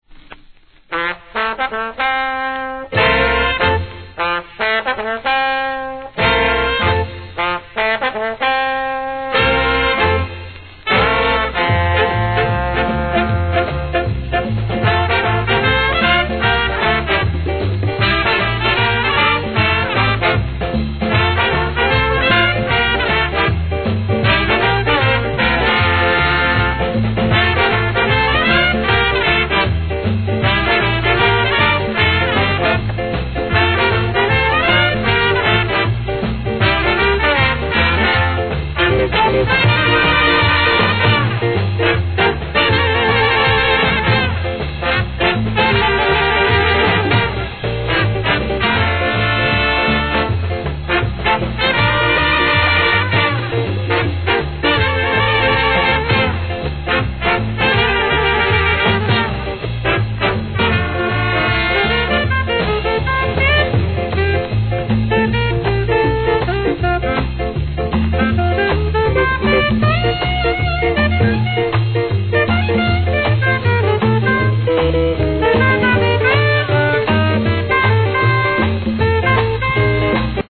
贅沢なSKA〜カリプソのコンピ!!